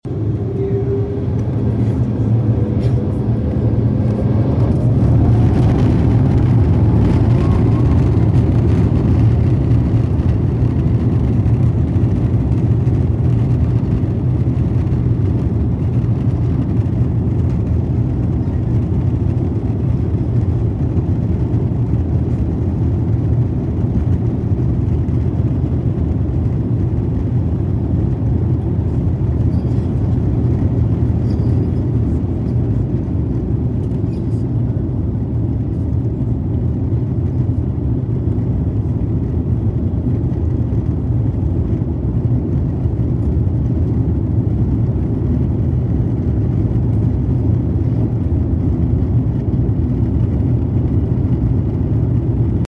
Departing LAX on the A350! sound effects free download